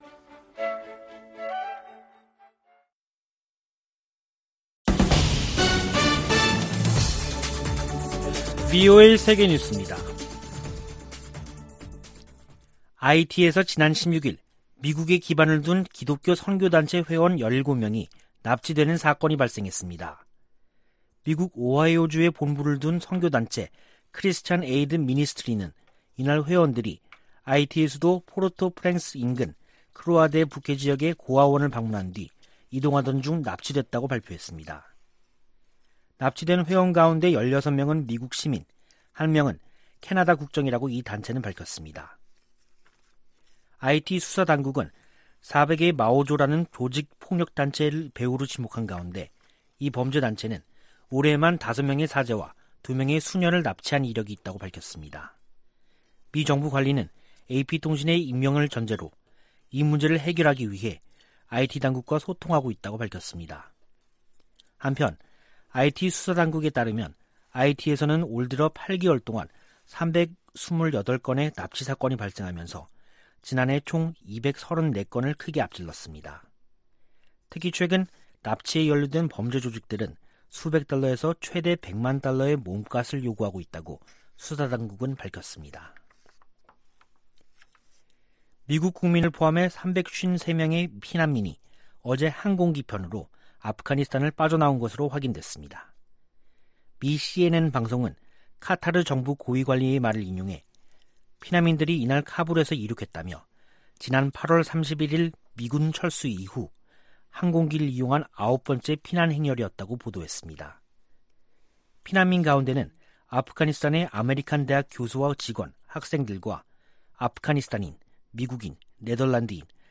VOA 한국어 간판 뉴스 프로그램 '뉴스 투데이', 2021년 10월 18일 3부 방송입니다. 미군 정보당국이 새 보고서에서 북한이 장거리 미사일 시험 발사와 핵실험을 재개할 수 있다고 전망했습니다. 미 국무부는 한반도의 완전한 비핵화 목표를 진전시키기 위해 한국, 일본과 긴밀한 협력을 지속하고 있다고 밝혔습니다. 이인영 한국 통일부 장관은 보건방역 분야 대북 인도적 협력 방안에 대해 미-한 간 구체적인 진전이 있다고 밝혔습니다.